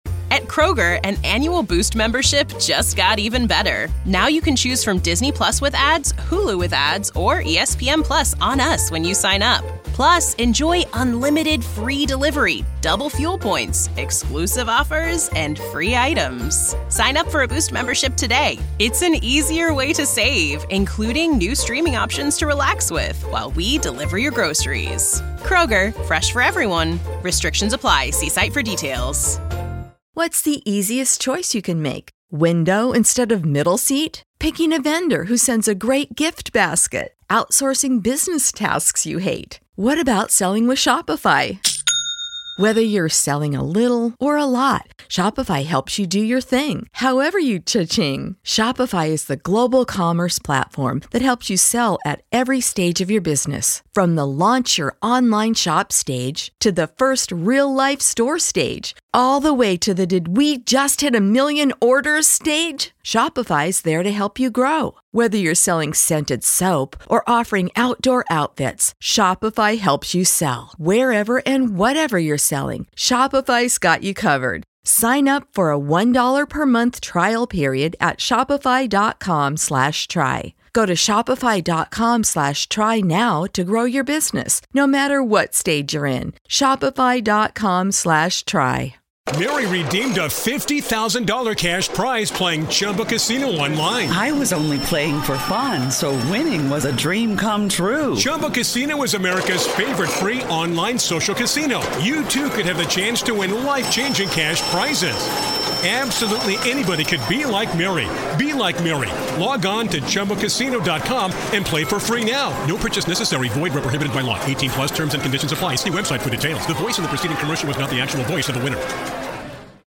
Equipment We Use From Amazon: USB Lavalier Lapel Microphone: